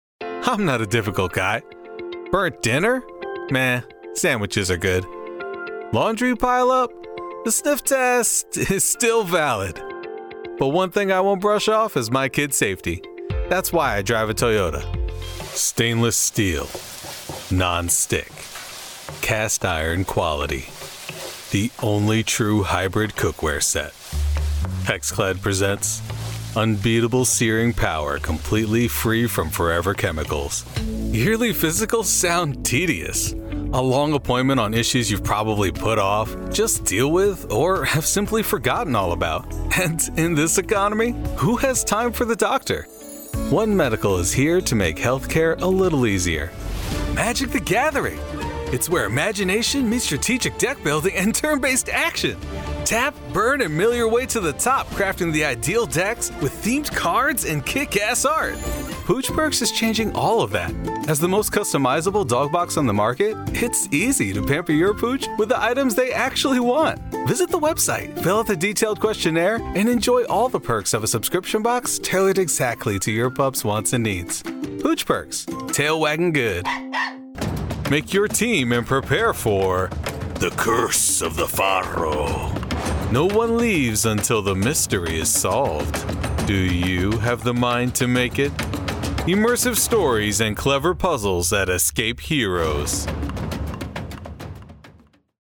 Formal Artistry Voiceover prides itself on being versatile, dynamic, and real.
Commercial Demo